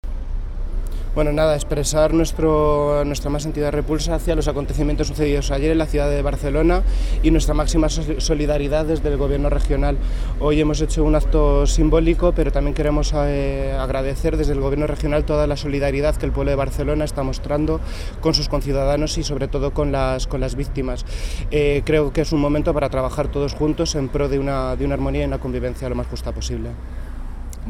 Declaraciones de Amador Pastor